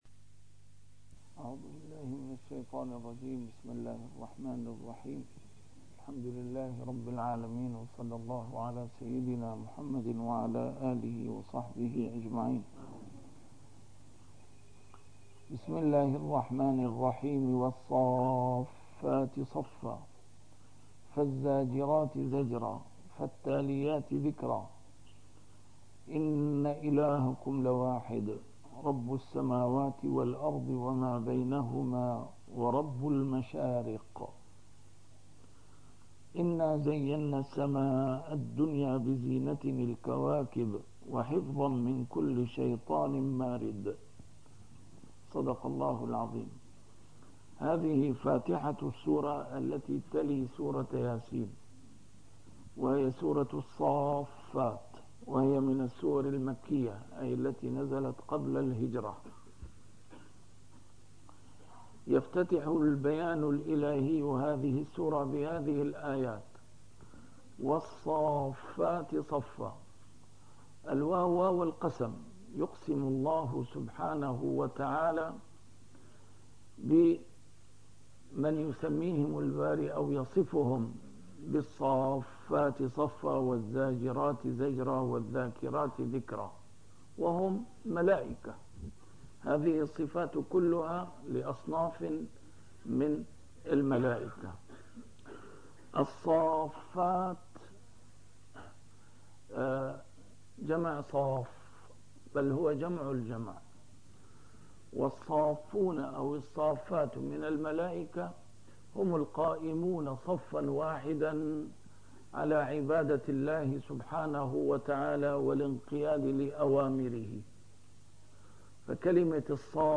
A MARTYR SCHOLAR: IMAM MUHAMMAD SAEED RAMADAN AL-BOUTI - الدروس العلمية - تفسير القرآن الكريم - تسجيل قديم - الدرس 444: الصافات 001-007